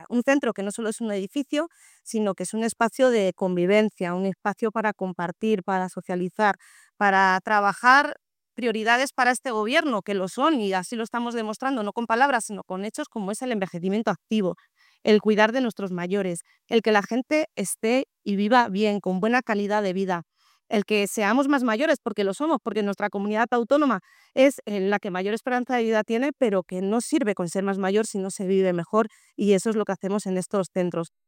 La consejera de Bienestar Social, Bárbara García Torijano
en la inauguración del nuevo Centro de Mayores y Centro de Día de Tarancón